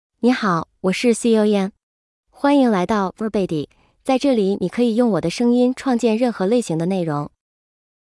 Xiaoyan — Female Chinese (Mandarin, Simplified) AI Voice | TTS, Voice Cloning & Video | Verbatik AI
Xiaoyan is a female AI voice for Chinese (Mandarin, Simplified).
Voice sample
Listen to Xiaoyan's female Chinese voice.
Female
Xiaoyan delivers clear pronunciation with authentic Mandarin, Simplified Chinese intonation, making your content sound professionally produced.